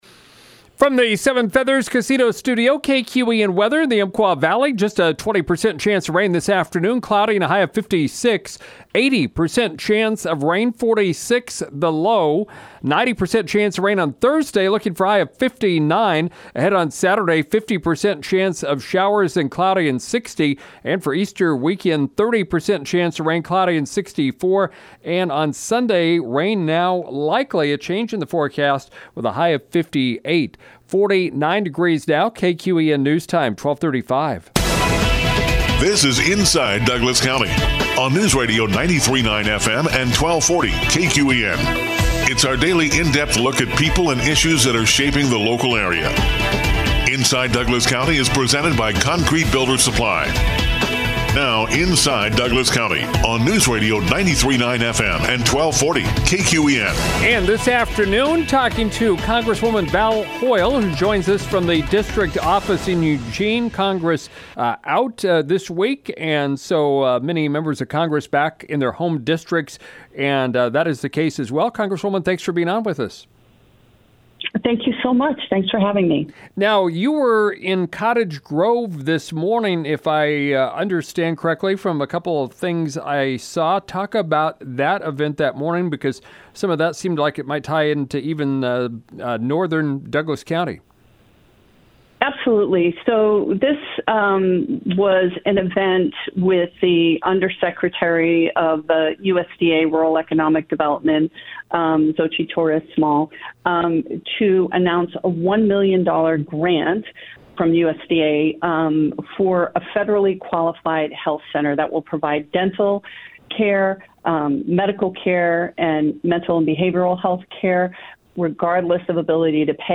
4th District Congresswoman Val Hoyle joined us from her Eugene office as she is back in the district while Congress is adjourned for their Easter Break.